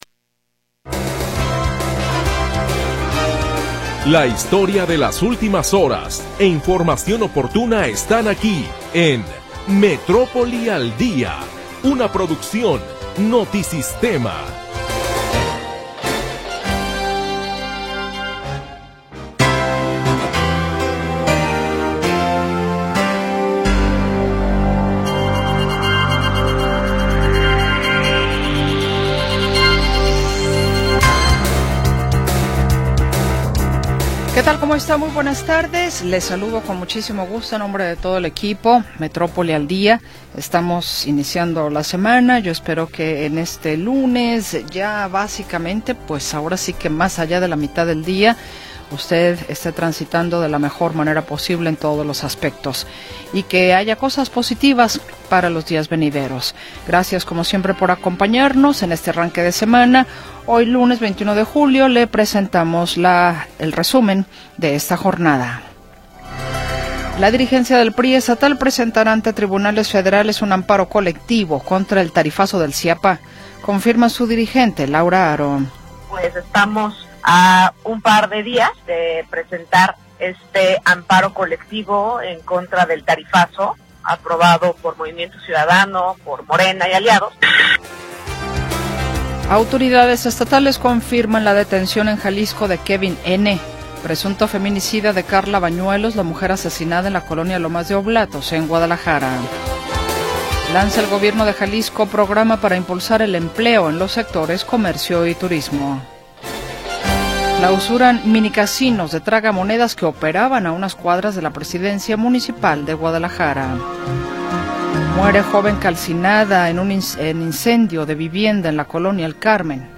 Primera hora del programa transmitido el 21 de Julio de 2025.